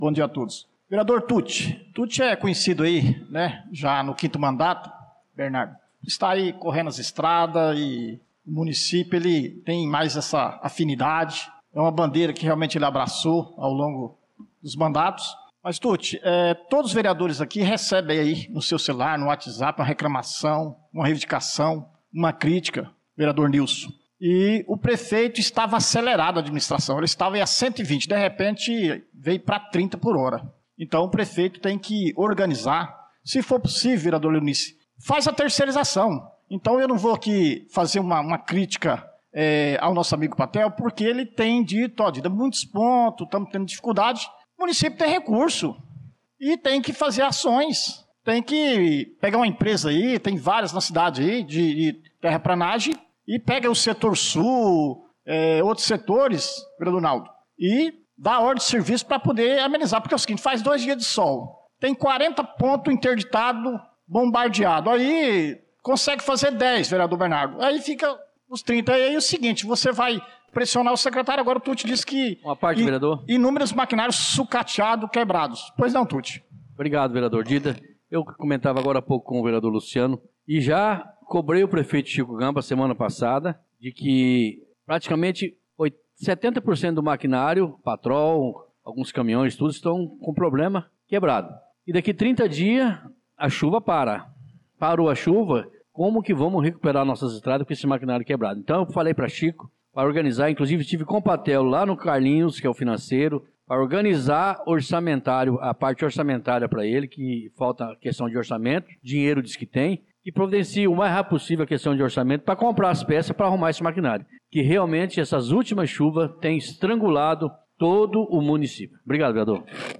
Pronunciamento do vereador Dida Pires na Sessão Ordinária do dia 11/03/2025